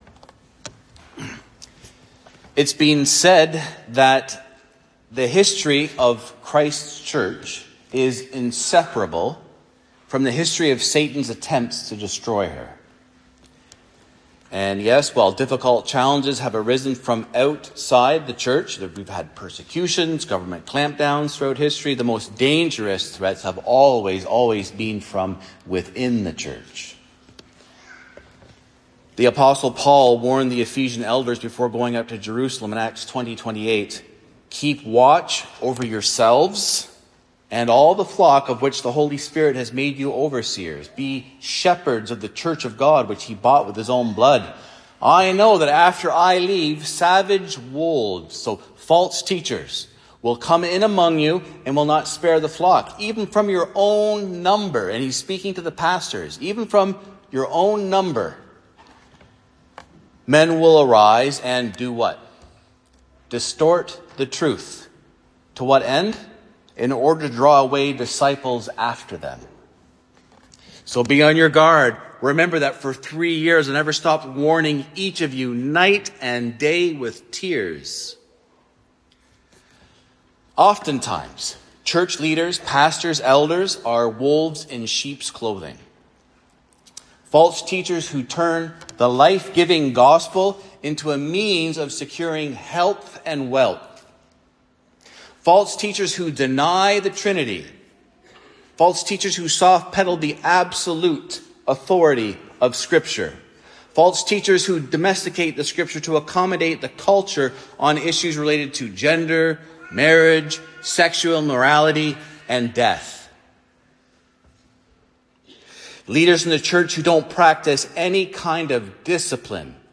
The sermons of Mount Pleasant Baptist Church in Toronto, Ontario.